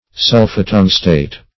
Search Result for " sulphotungstate" : The Collaborative International Dictionary of English v.0.48: Sulphotungstate \Sul`pho*tung"state\, n. (Chem.) A salt of sulphotungstic acid.